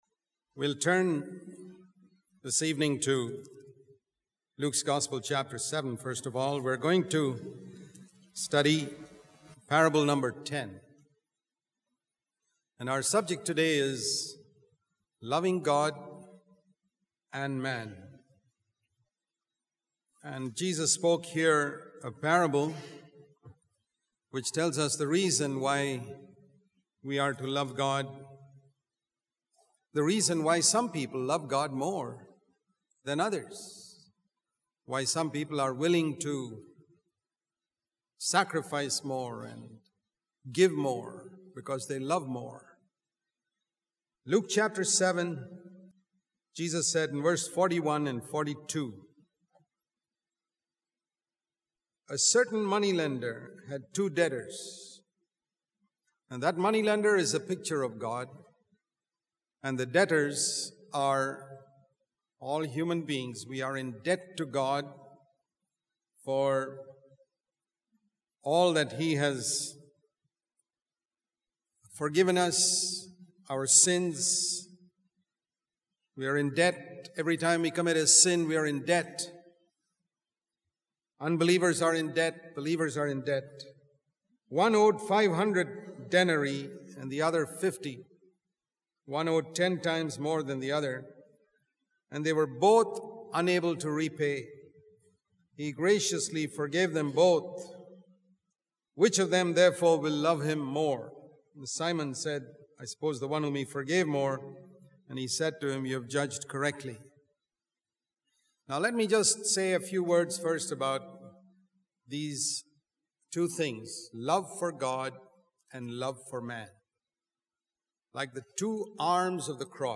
In this sermon, the speaker focuses on two parables from the book of Luke and Matthew. The first parable is about a moneylender who forgives the debts of two debtors, illustrating how we are all in debt to God for our sins.